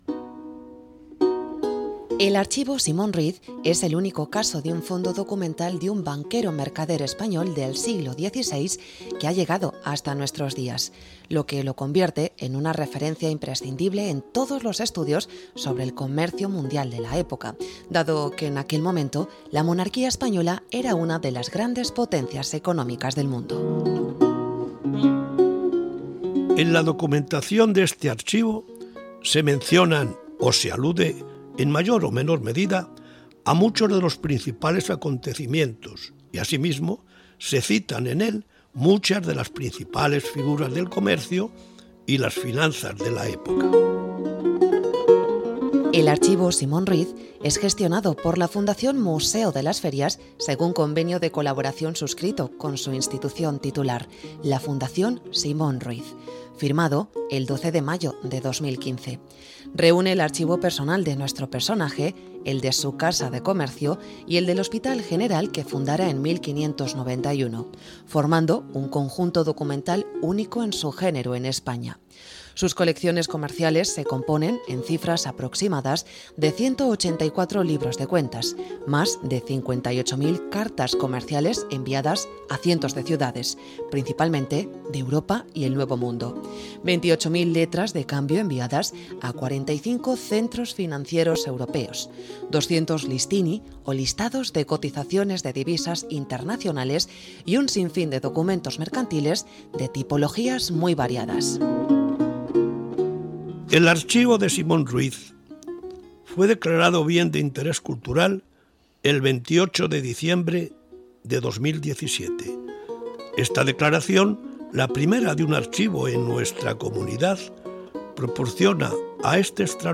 Locuciones generales de capítulos con documentos del Archivo Simón Ruiz